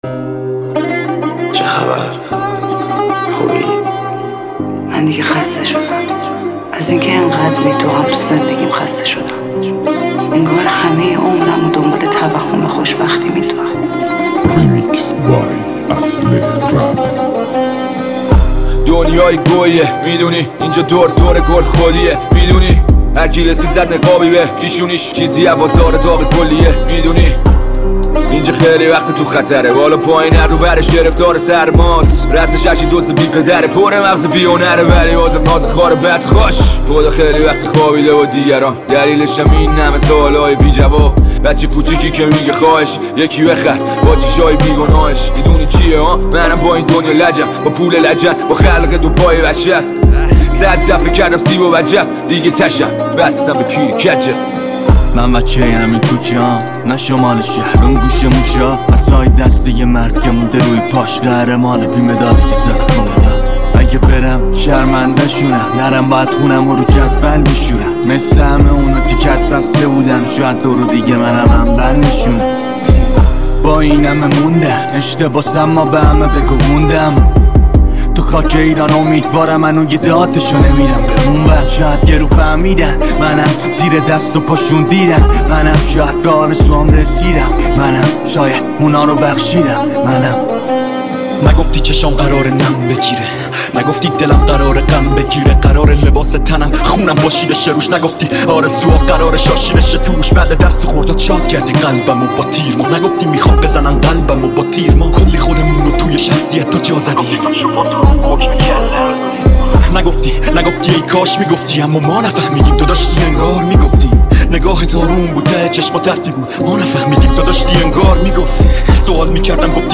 ریمیکس جدید رپ